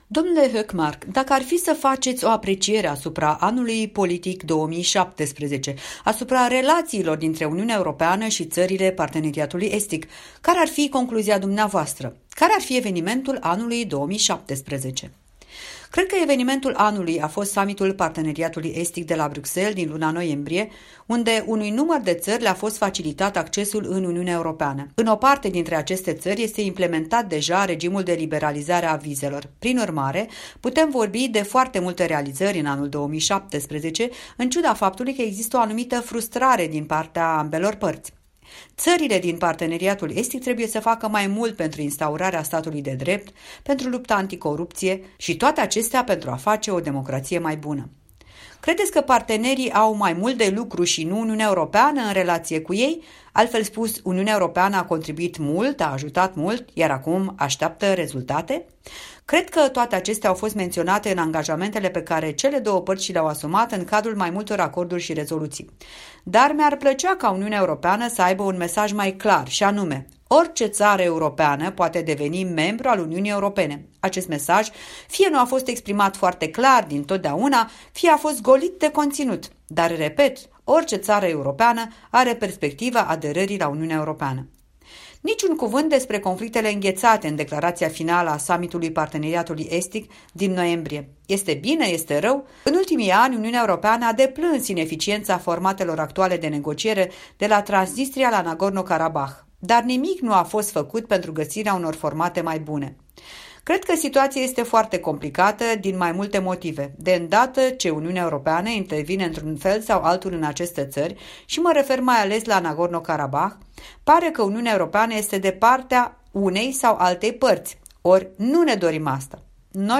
Un interviu cu preşedintele Delegaţiei Suedeze în Parlamentul European, vicepreședinte al Grupului PPE.
Interviu cu Gunnar Hökmark